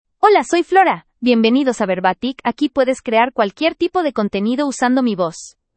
FloraFemale Spanish AI voice
Flora is a female AI voice for Spanish (United States).
Voice sample
Listen to Flora's female Spanish voice.
Flora delivers clear pronunciation with authentic United States Spanish intonation, making your content sound professionally produced.